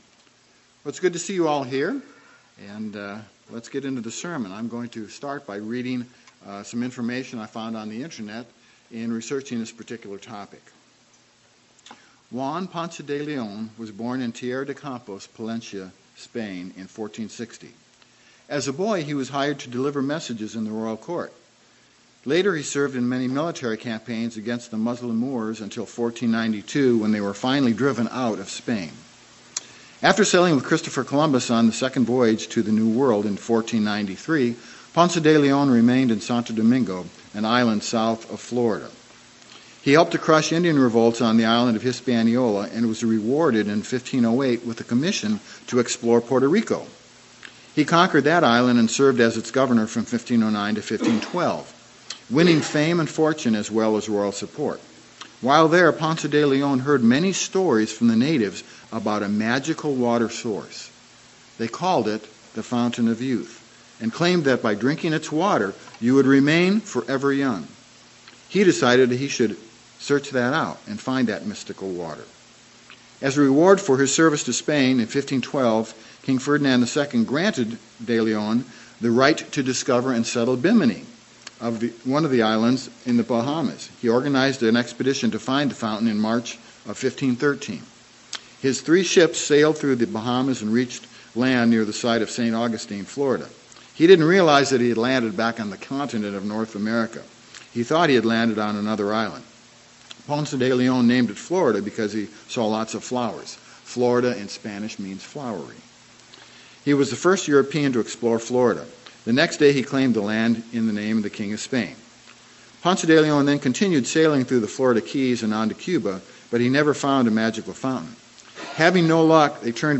Sermons
Given in Lawton, OK